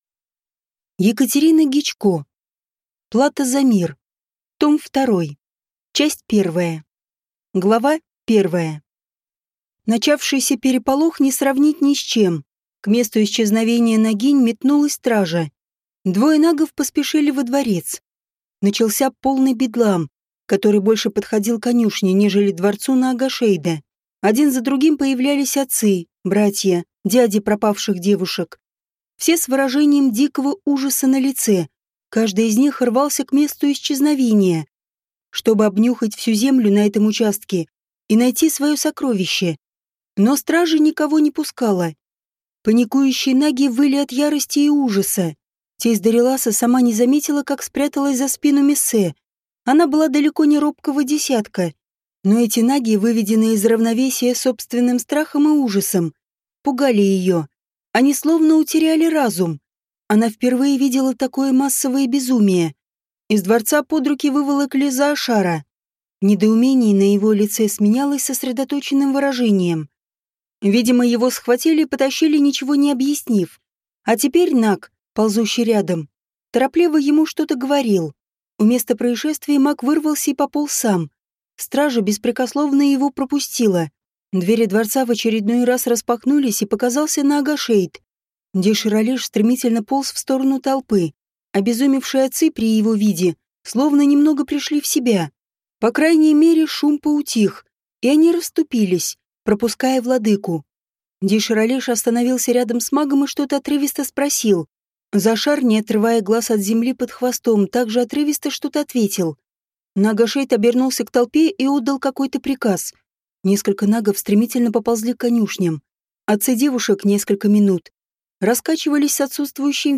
Аудиокнига Плата за мир. Том 2 | Библиотека аудиокниг
Прослушать и бесплатно скачать фрагмент аудиокниги